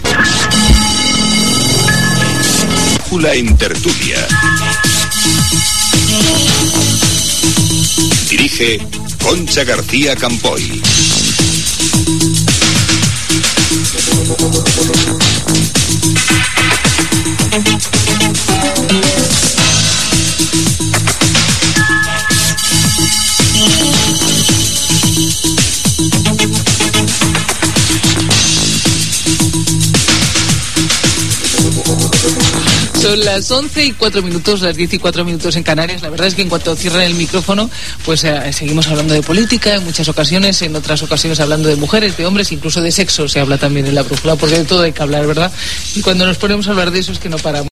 Indicatiu "La brújula en tertulia", hora, del que es parla quan es tanca el micròfon
Informatiu